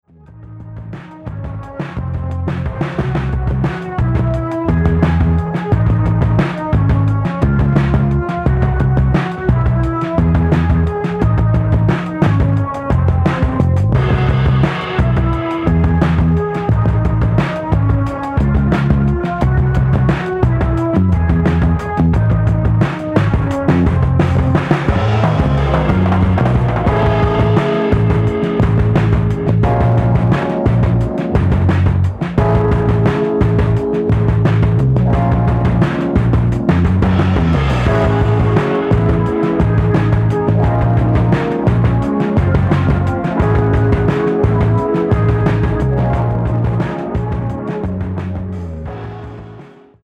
UK Jazz act
Broken beat, Experimental, Jazz